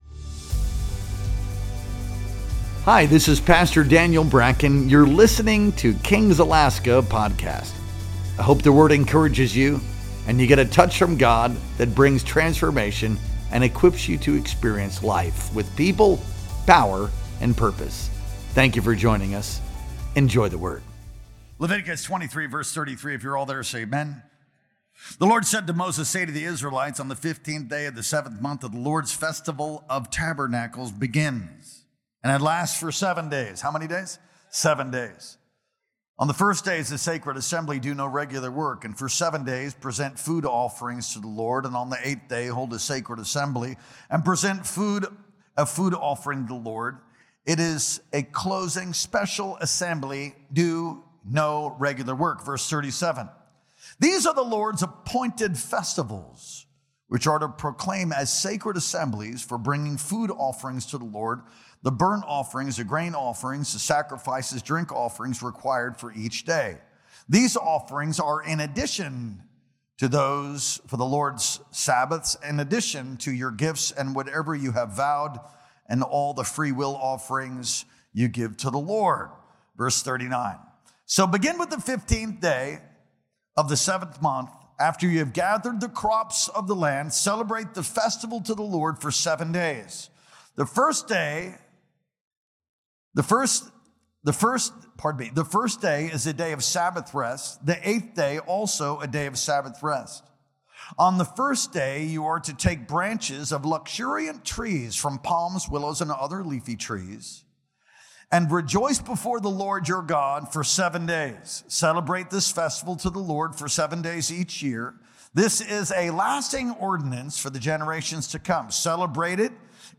Our Sunday Night Worship Experience streamed live on October 12th, 2025.